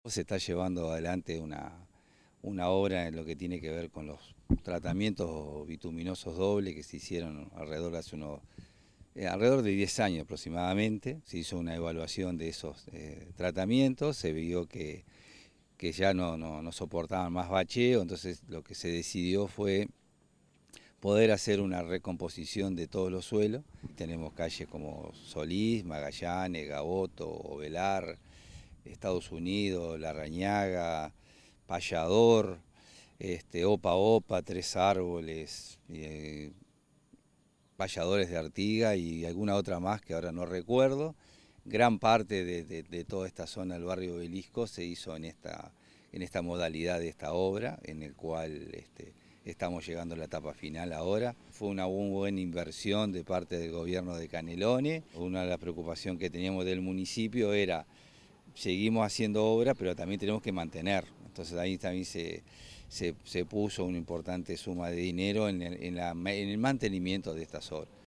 alcalde_gustavo_gonzalez_0.mp3